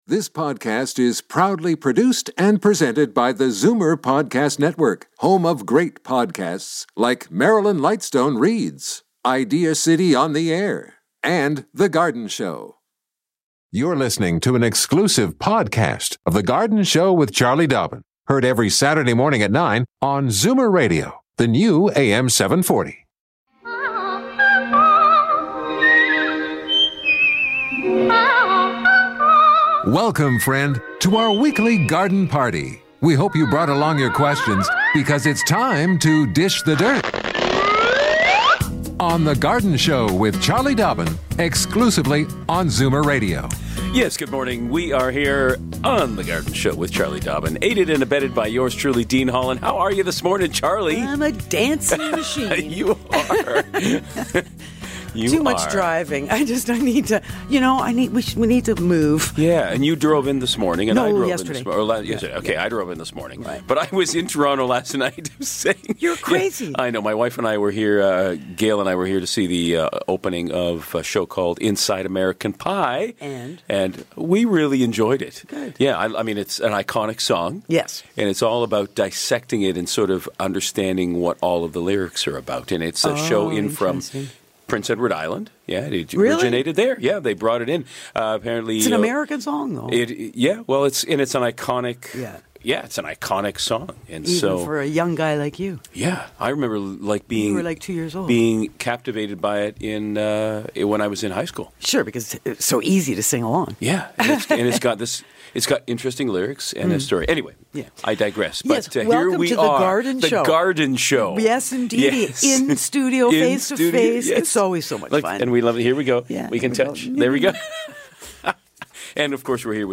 Callers are interested in taking geranium cuttings, caring for 'mother-in-laws tongue, starting seeds and filling raised gardens.